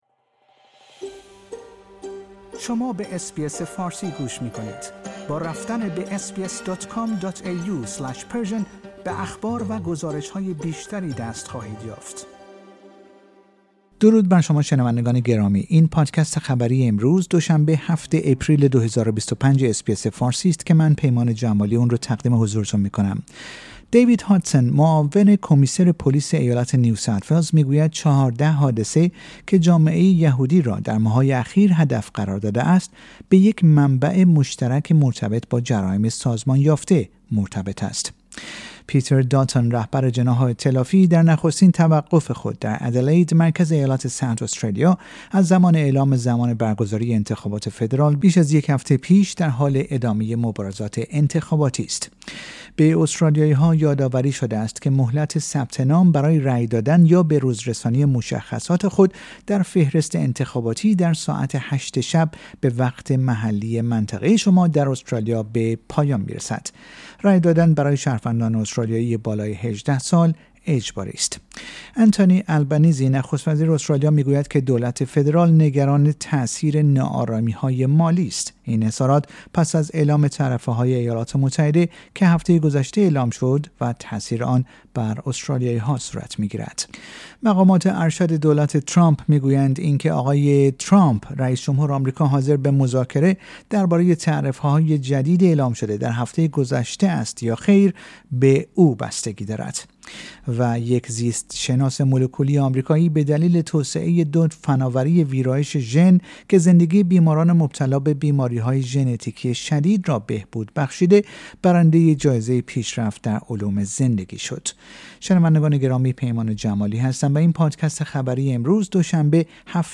در این پادکست خبری مهمترین اخبار هفته منتهی به دوشنبه هفتم آپریل ارائه شده است.